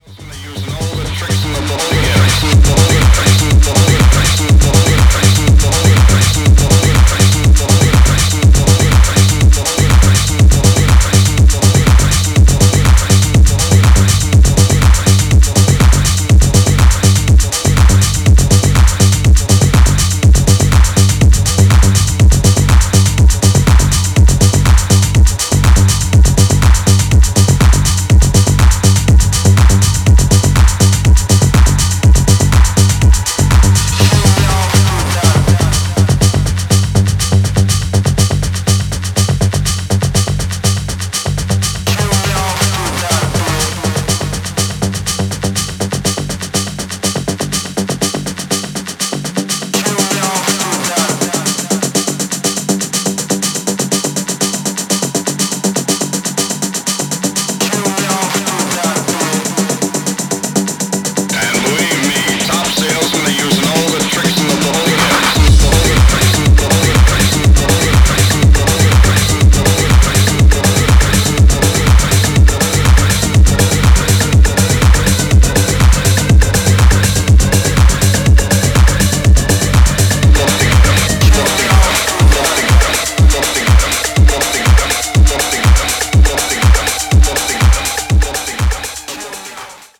Electro Acid